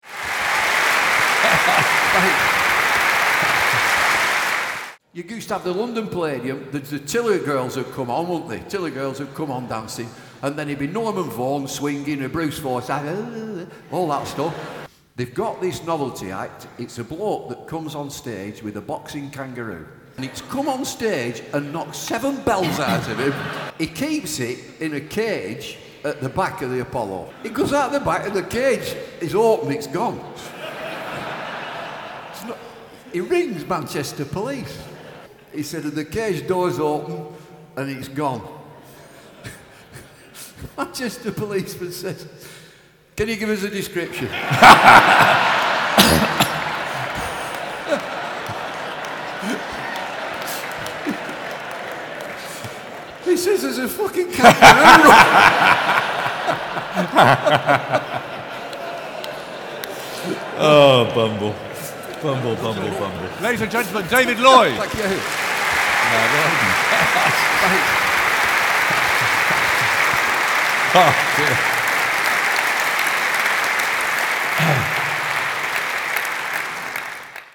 Here are some snippets from our live and recorded work.